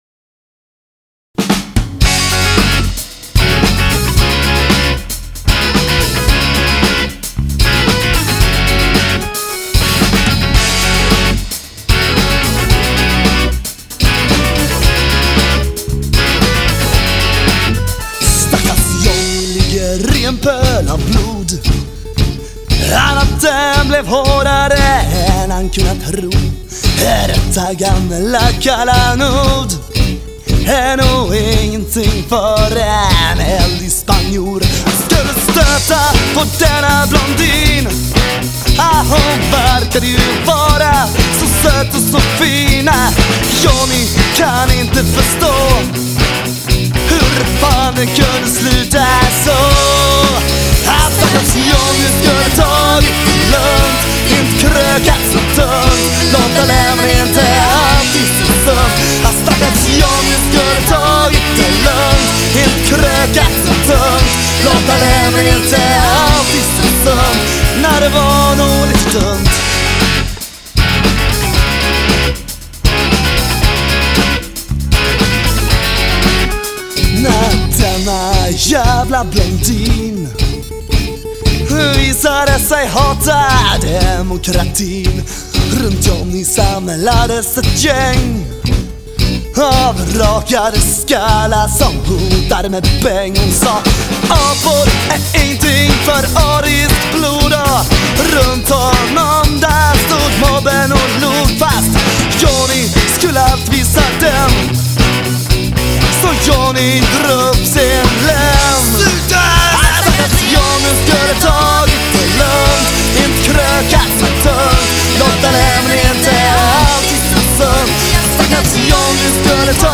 Inspelad, under en dag i Sveriges Radio våren 2003
gitarr & sång
orgel
trummor
kör